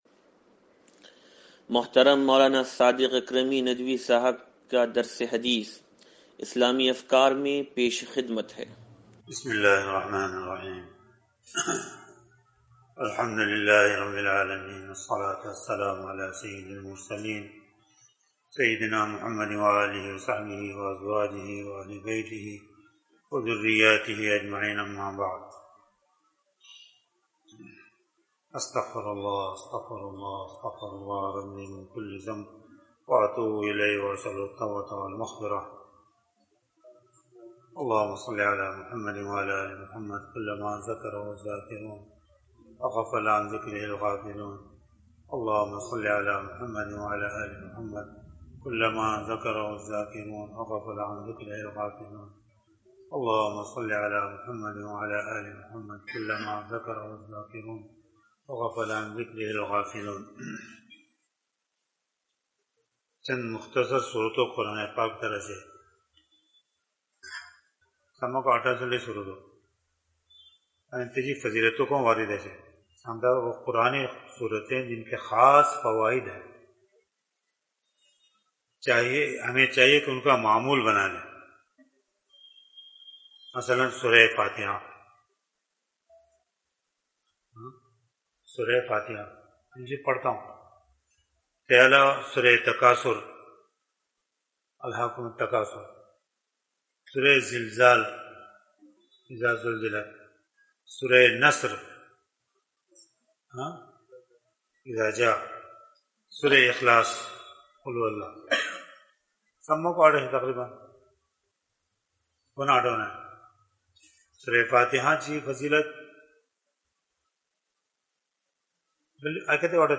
درس حدیث نمبر 0488